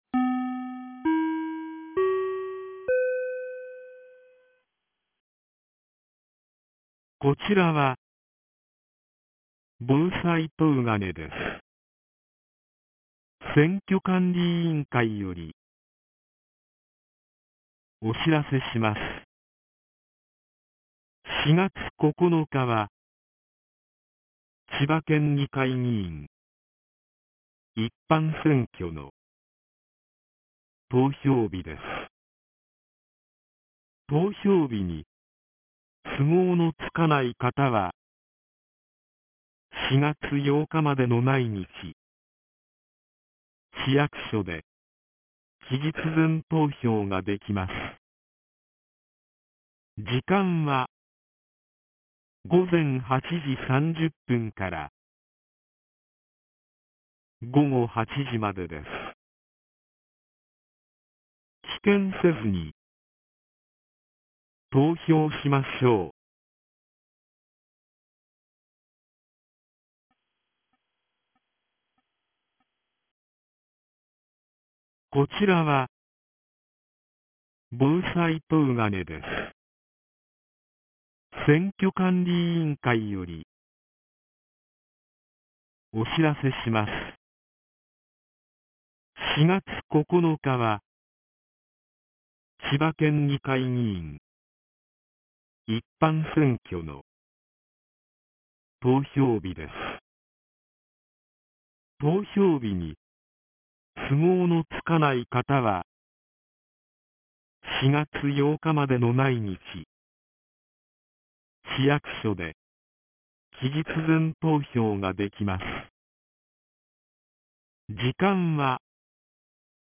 2023年04月07日 13時33分に、東金市より防災行政無線の放送を行いました。